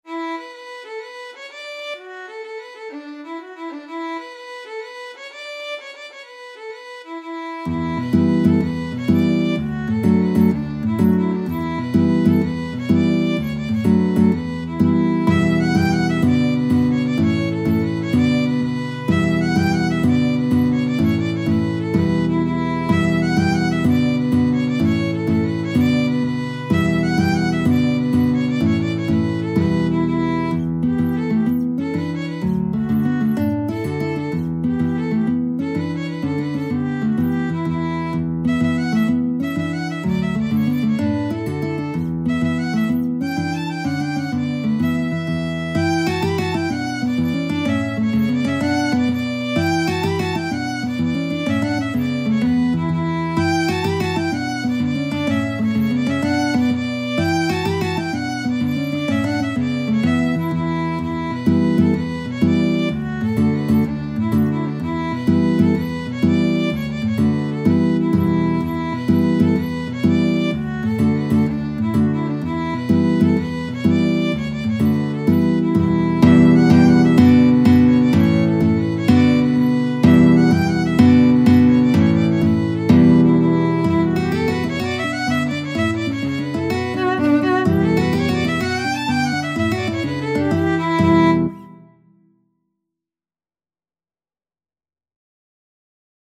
Free Sheet music for Violin-Guitar Duet
D major (Sounding Pitch) (View more D major Music for Violin-Guitar Duet )
Fast .=c.126
12/8 (View more 12/8 Music)
Classical (View more Classical Violin-Guitar Duet Music)
Irish